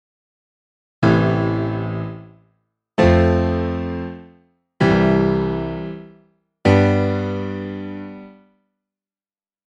progression-i-vi-iii-vii.mp3